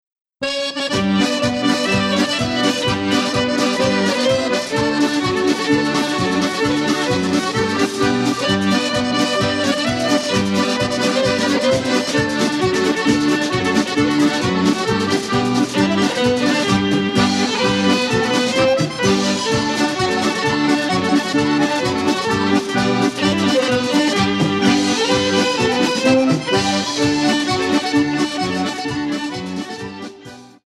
accordion
violin